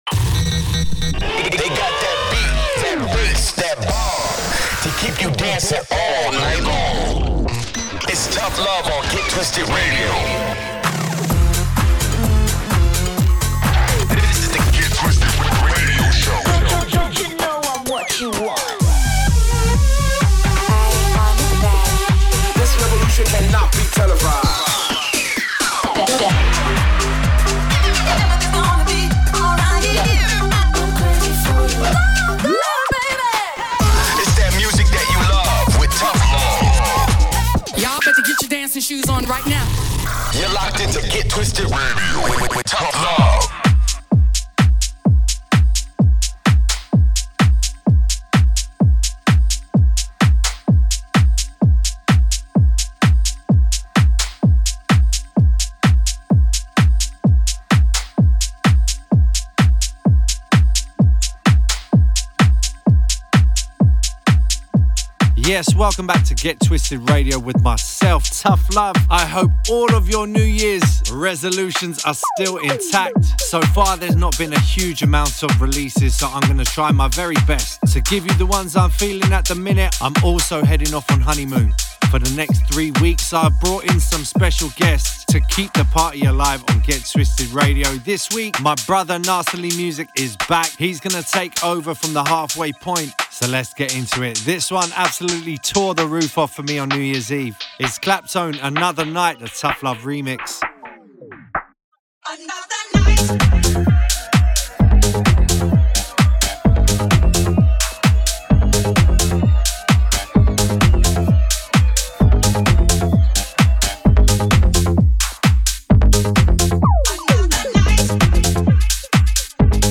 live & direct